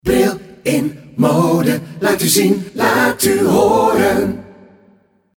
passende, pakkende melodielijn